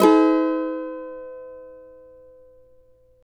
CAVA A#MN  U.wav